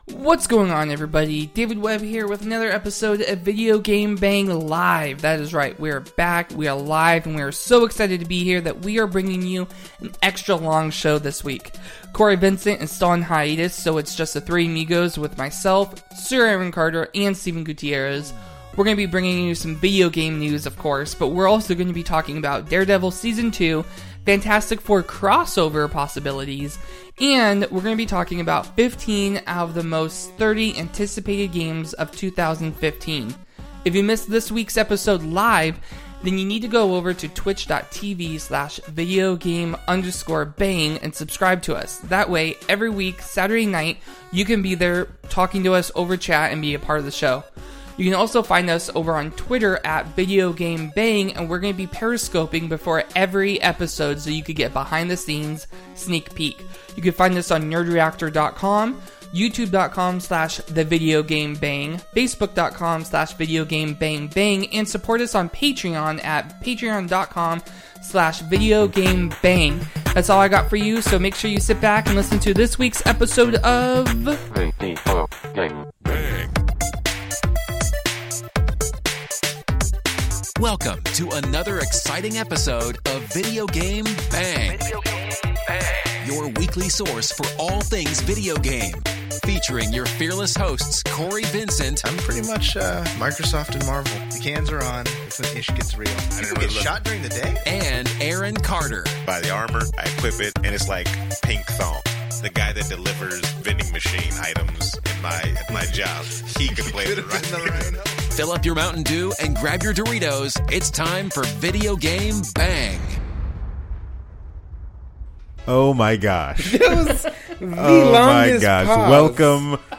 VGB is back with a live show and we are going into overtime for you.